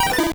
Cri de Têtarte dans Pokémon Or et Argent.